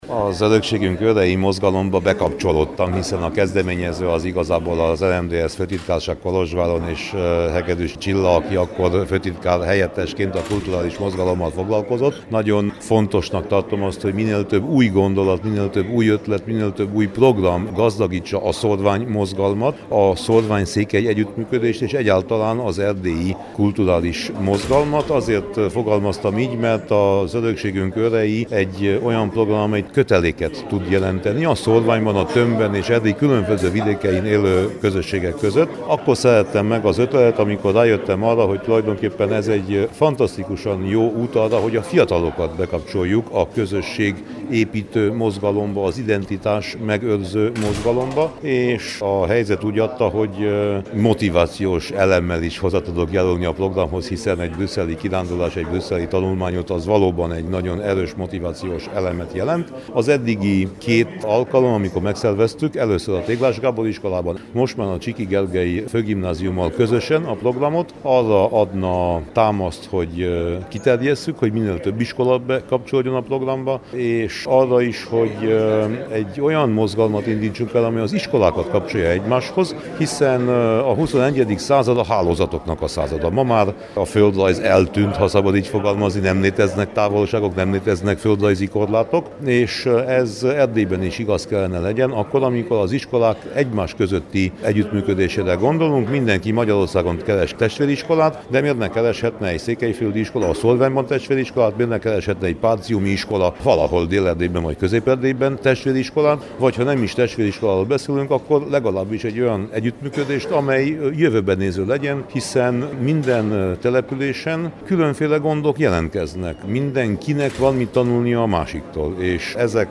A Hunyad megyei RMDSZ-es tisztségviselő a múlt pénteken az aradi gazdafórumon vett részt,